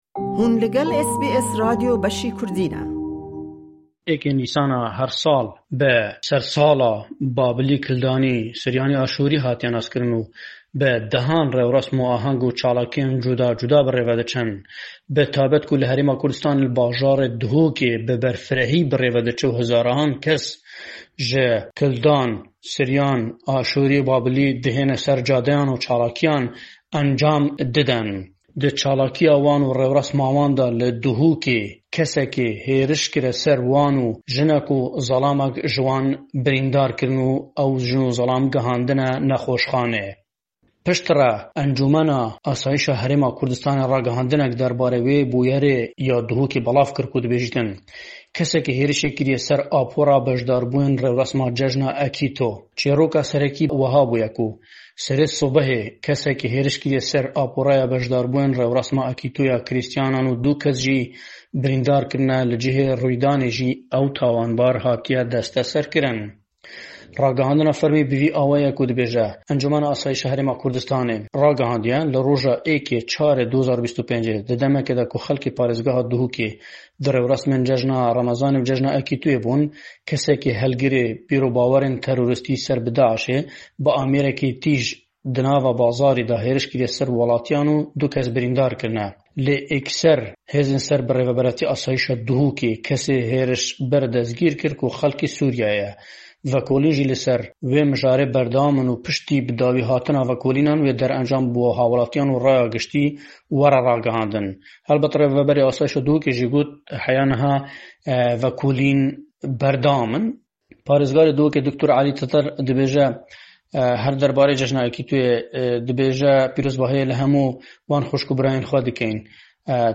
Raporta
ji Hewlere.